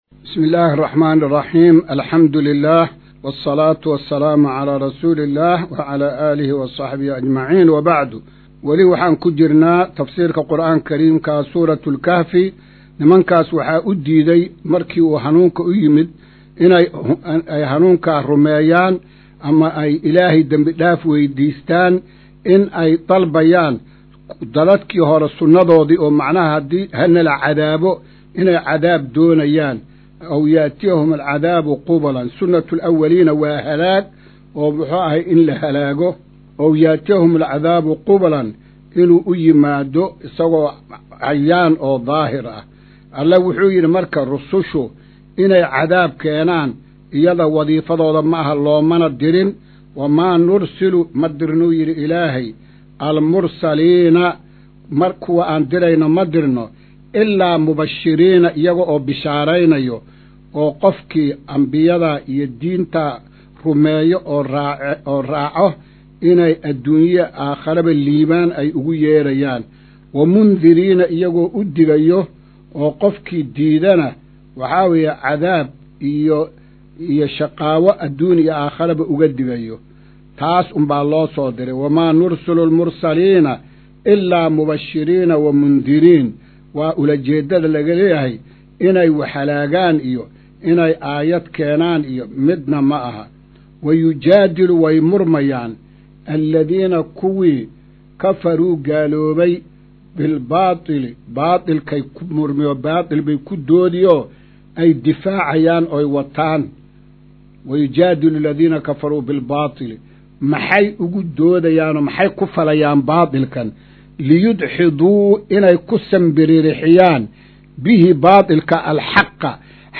Maqal:- Casharka Tafsiirka Qur’aanka Idaacadda Himilo “Darsiga 146aad”